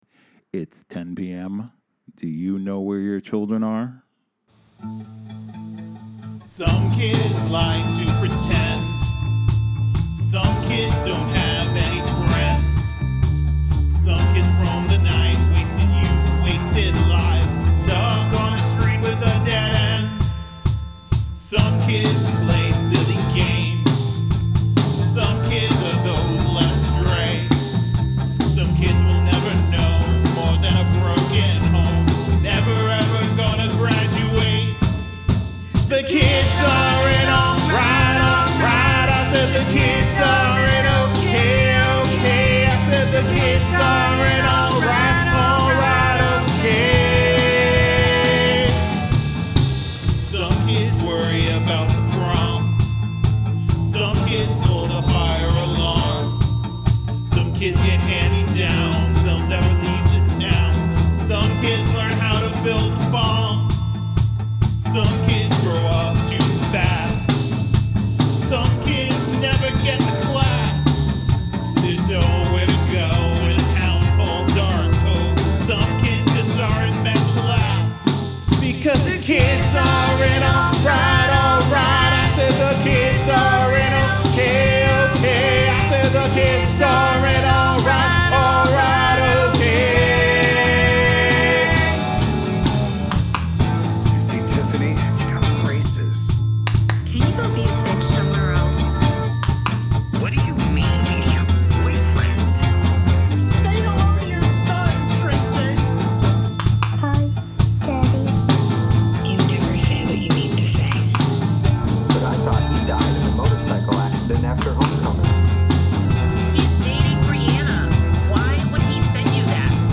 Make use of handclaps and snaps